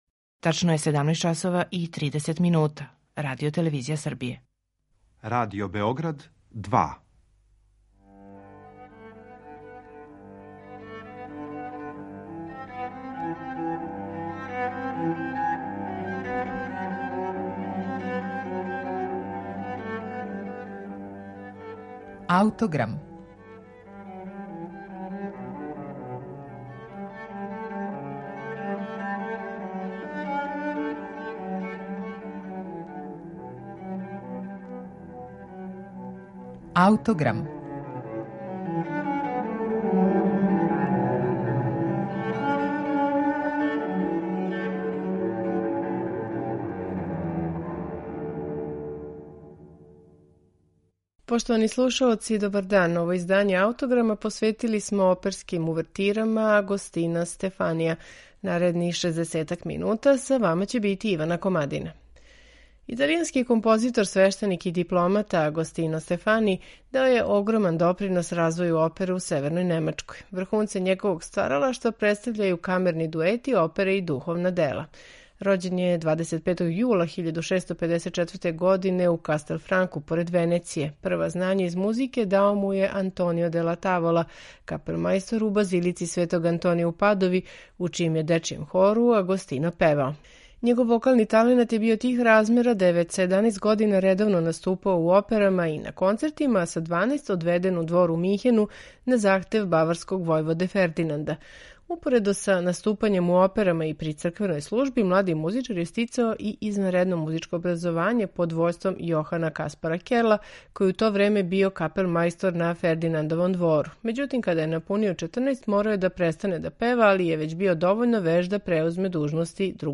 Увертире, оркестарски интерлудијуми и балетски међучинови опера Агостина Стефанија сведоче о ауторовој мелодијској инвентивности, маштовитим ритмичким и оркестрационим решењима и огромном таленту.
За данашњи Аутограм издвојили смо увертире и оркестарске интерлудијуме из Стефанијевих опера, које ћете слушати у интерпретацији ансамбла „I barocchisti", под управом Дијега Фазолиса.